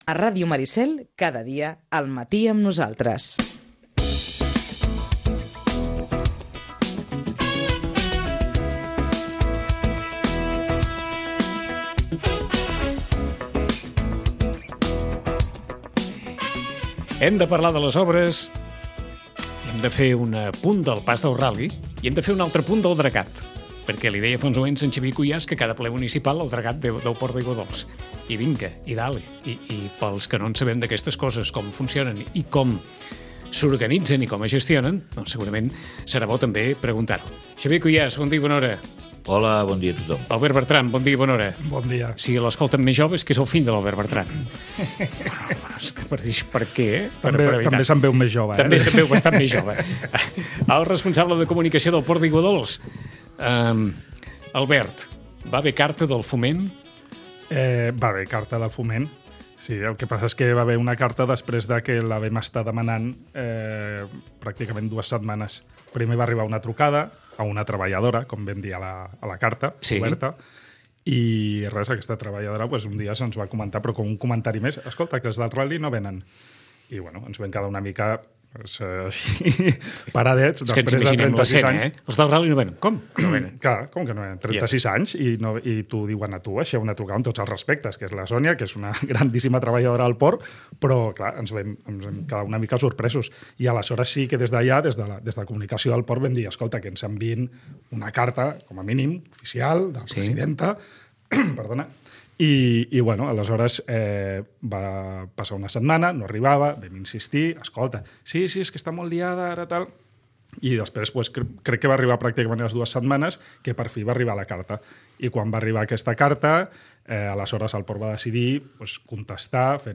El més urgent hores d’ara és poder dragar la bocana que amb prou feines arriba als 2,5m de calat, quan ha d’arribar a una profunditat d’entre 4,5-5m per poder assegurar una activitat portuària sense problemes. La conversa ha començat parlant del pas del ral·li pel port i ha acabat fent referència a la regularització dels habitatges del poblat mariner.